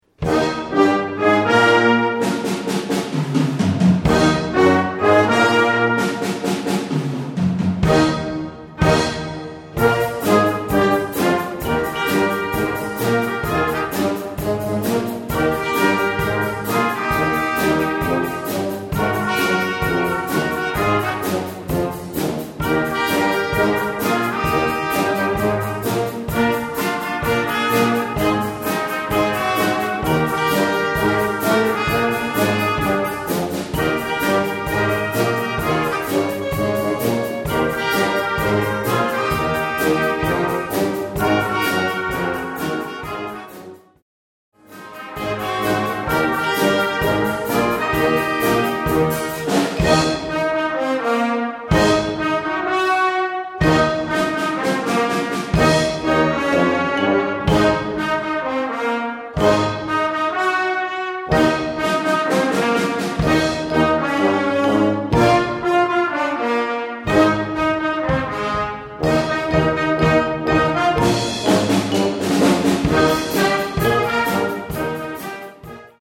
Gattung: Jugendwerk
Besetzung: Blasorchester
Boogie Woogie